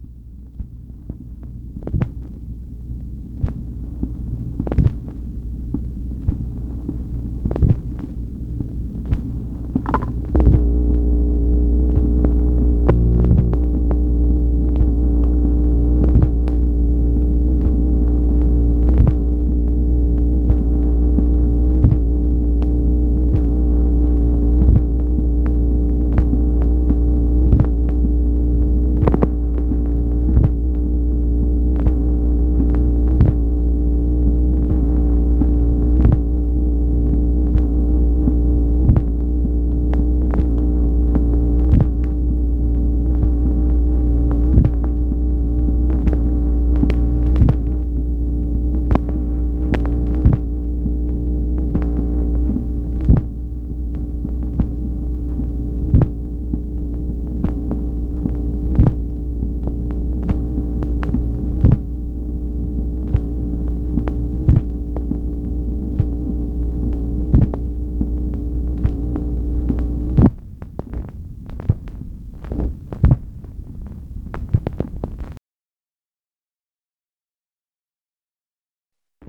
MACHINE NOISE, February 7, 1964
Secret White House Tapes | Lyndon B. Johnson Presidency